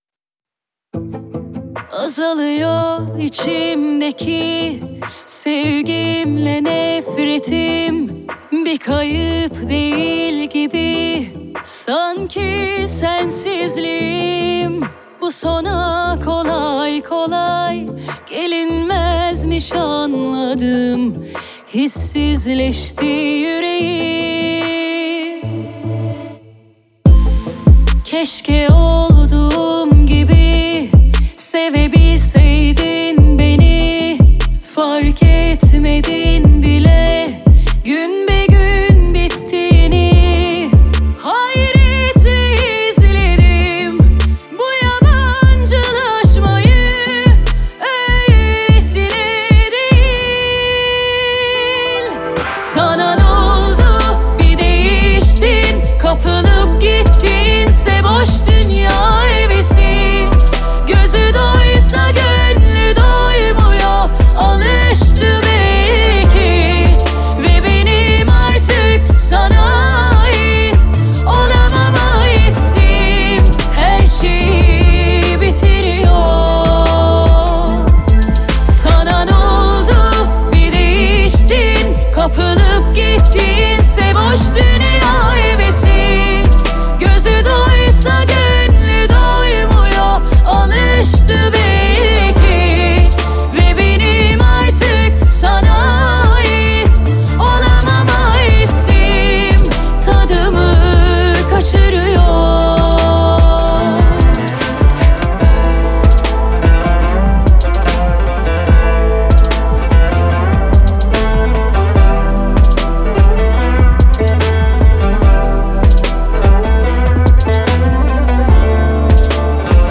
آهنگ ترکیه ای آهنگ غمگین ترکیه ای آهنگ هیت ترکیه ای ریمیکس